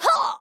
fall_2.wav